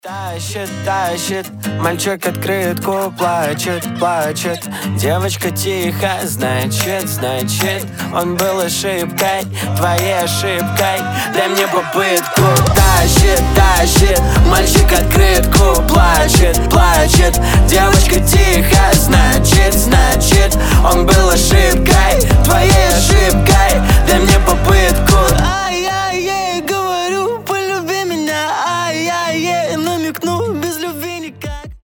• Качество: 320, Stereo
гитара
веселые